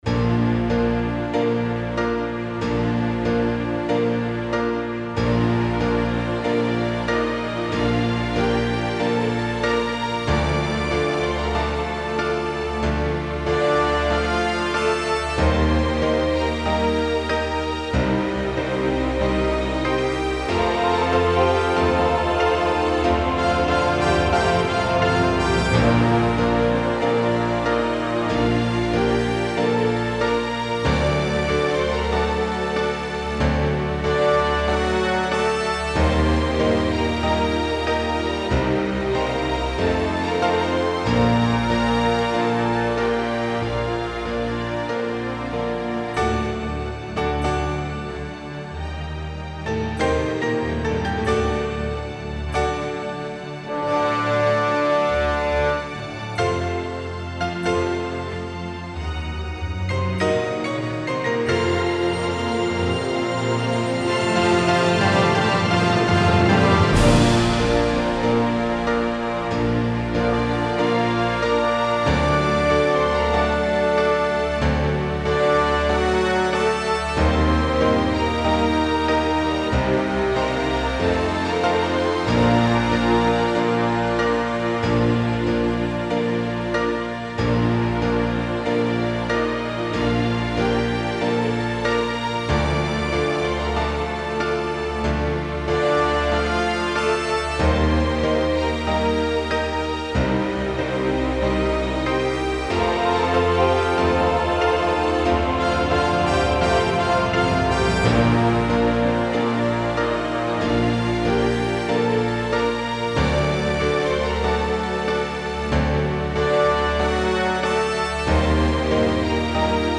#1 = #4   - оркестровое исполнение